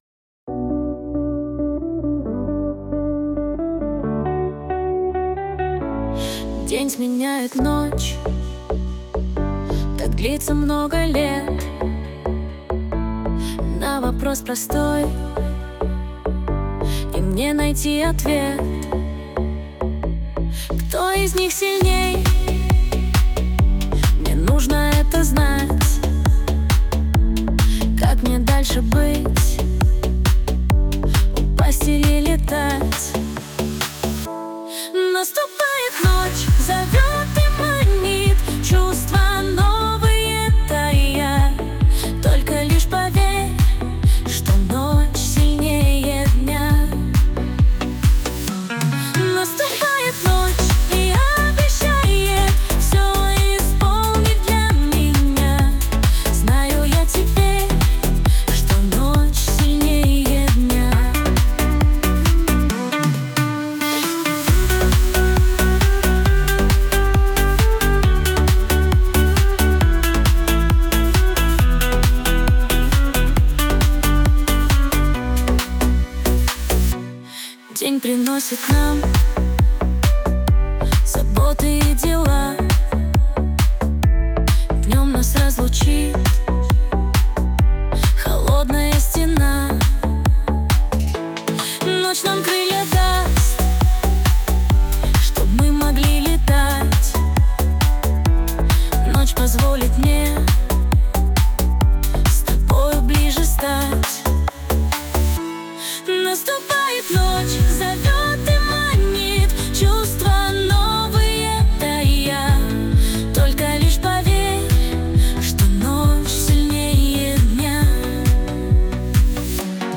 RUS, Dance, Pop, Disco | 16.03.2025 10:29